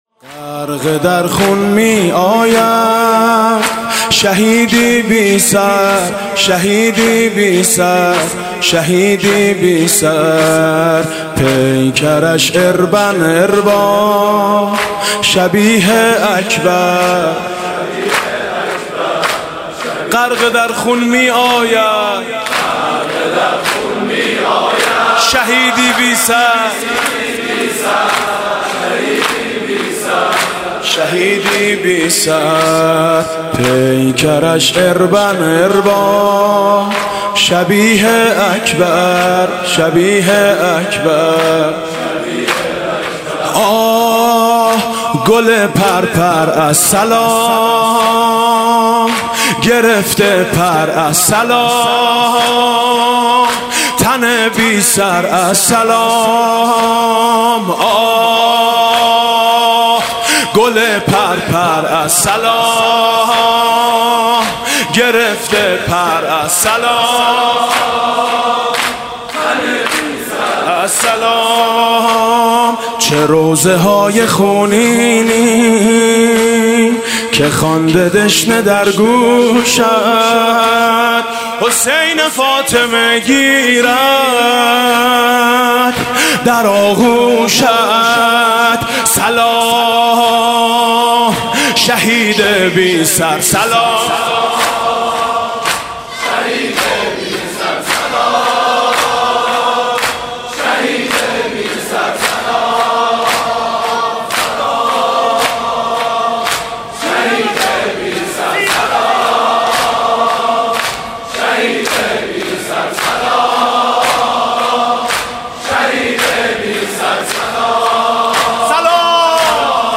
مداحی مطیعی درباره شهید حججی در شب پنجم محرم
شب گذشته میثم مطیعی در هیئت میثاق با شهداء که در مسجد دانشگاه امام صادق(ع) برگزار شد، به مدیحه سرایی درباره بازگشت پیکر مطهر این شهید پرداخت که در ادامه صوت آن منتشر می شود: علاقه‌مندان در بخش پیوست این خبر می‌توانند فایل صوتی این مداحی را دریافت کنند.